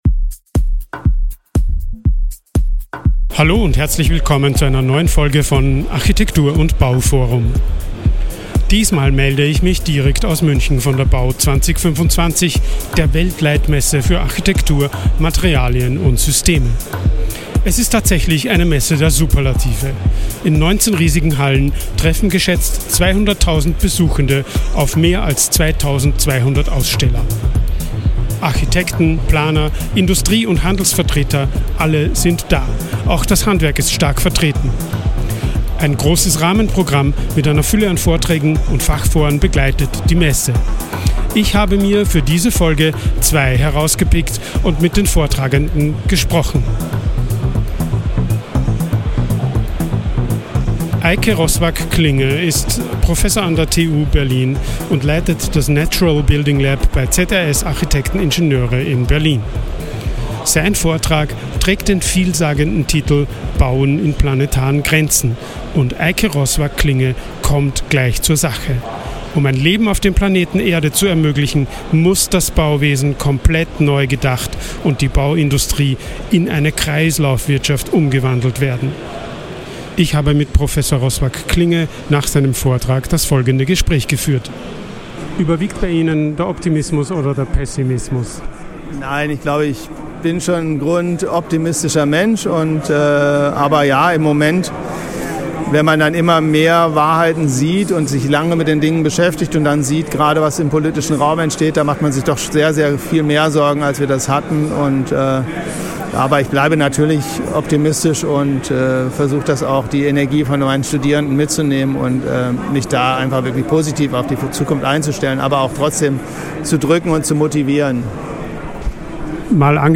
Dort hat er zwei spannende Gespräche geführt.